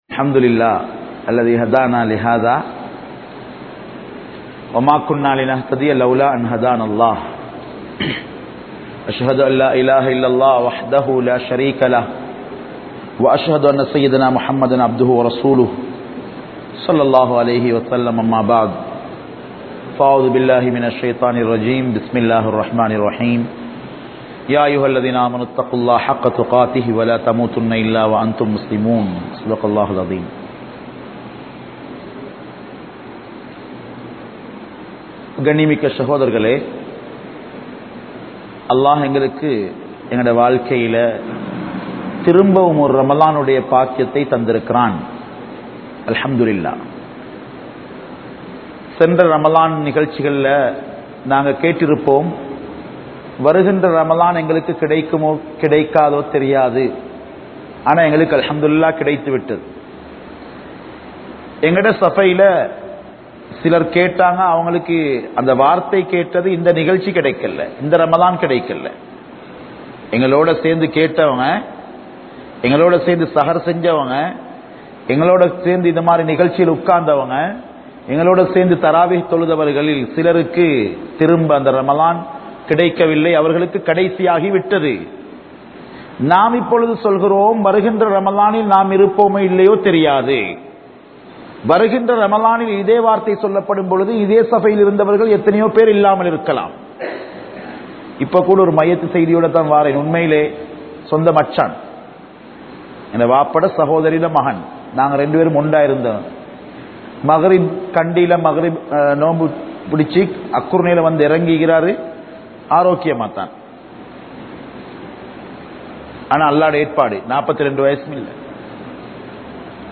Ramalanum Thawbavum (ரமழானும் தௌபாவும்) | Audio Bayans | All Ceylon Muslim Youth Community | Addalaichenai
Theliyagonna Jumua Masjidh